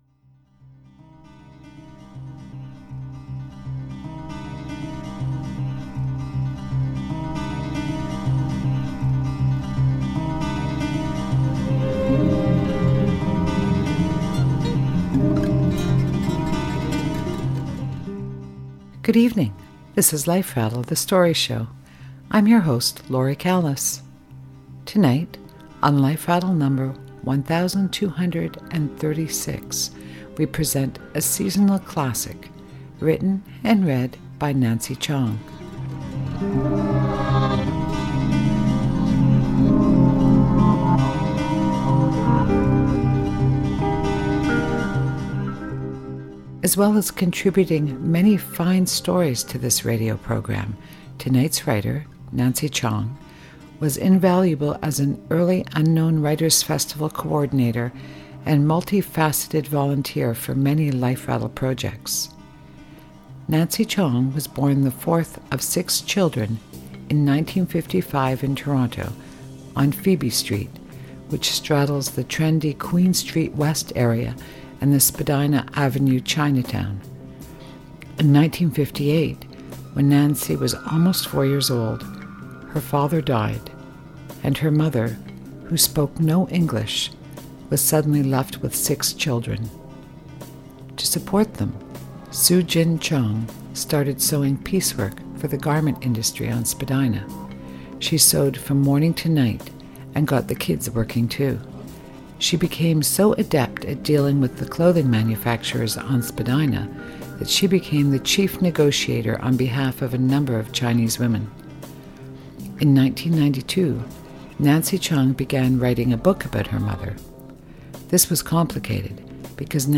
The recording of this story has been transferred from cassette tape, and the quality of the sound is not as good as it should be, but after you listen, you’ll know why we couldn’t leave this story on the shelf.